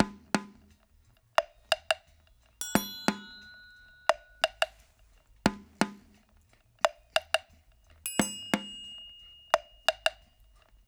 88-PERC2.wav